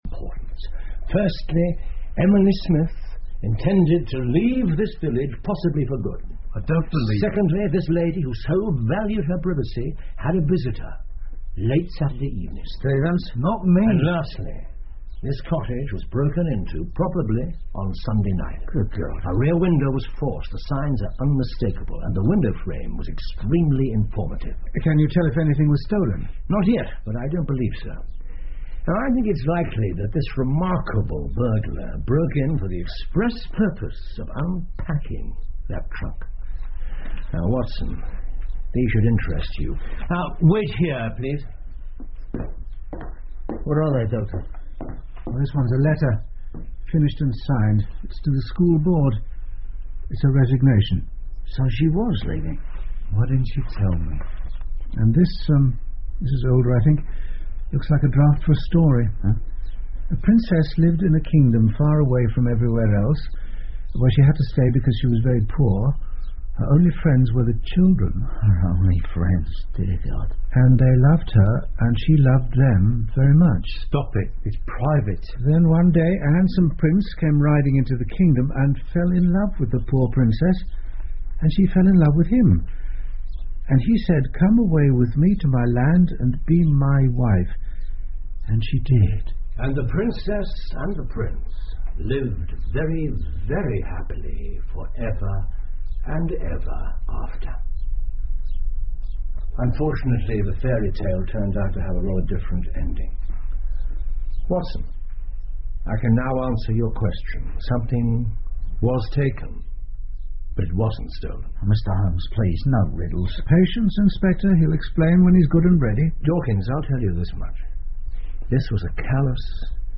福尔摩斯广播剧 The Shameful Betrayal Of Miss Emily Smith 6 听力文件下载—在线英语听力室